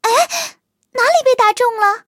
卡尔臼炮小破语音2.OGG